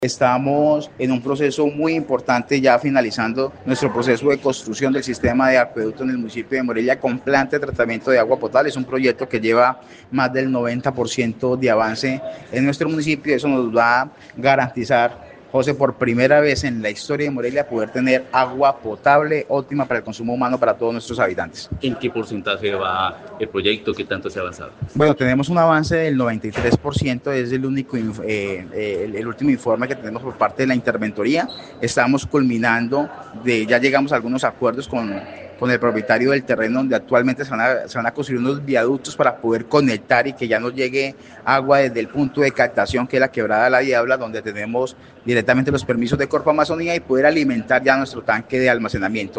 Así lo dio a conocer el alcalde del municipio de Morelia, Carlos Trujillo.